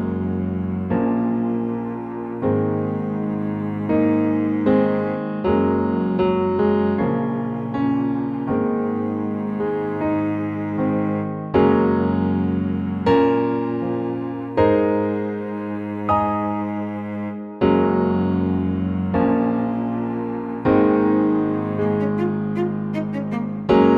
Female Key